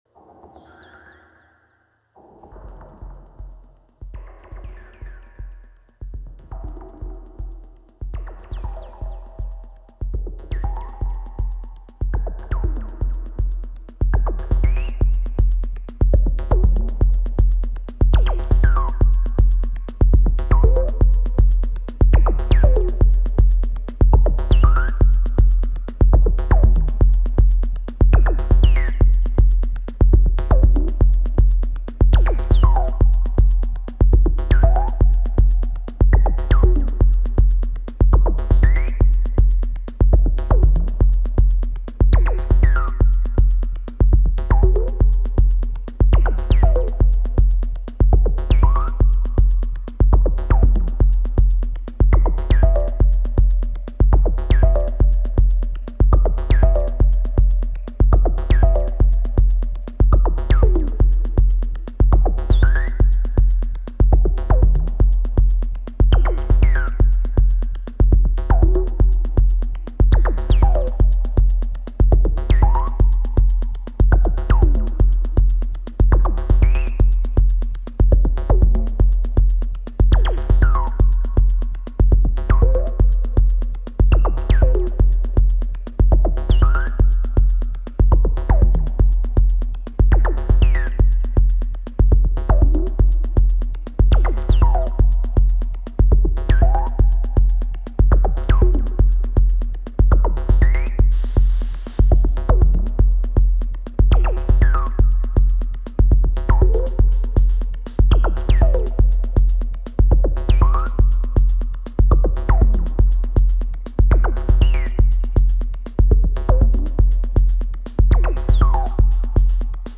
Live Recording 02.07.2019 23:49 with:
Volca Beats, Bass, Keys and Arpeggios with Arturia Keystep